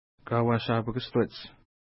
Kauashapakueshteti Next name Previous name Image Not Available ID: 180 Longitude: -61.4423 Latitude: 55.2884 Pronunciation: ka:wa:ʃa:pukəstwets Translation: Where Canvas Tents Are Visible From a Distance Feature: mountain